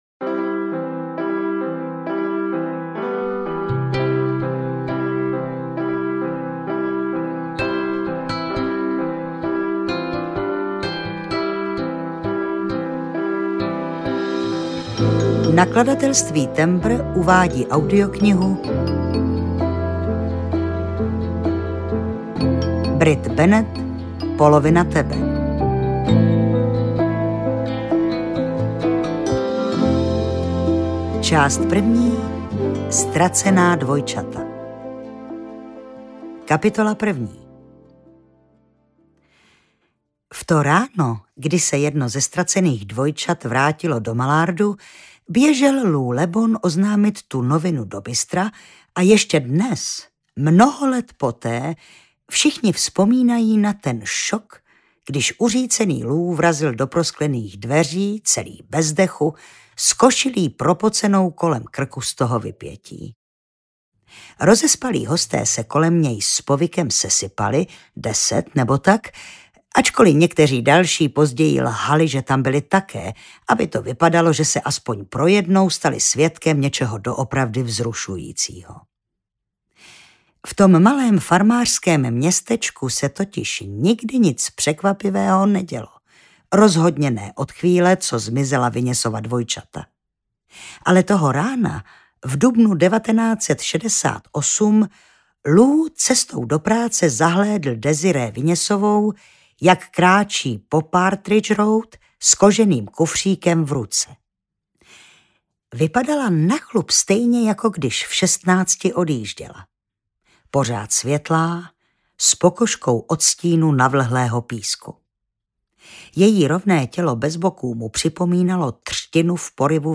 Polovina tebe audiokniha
Ukázka z knihy
• InterpretZuzana Slavíková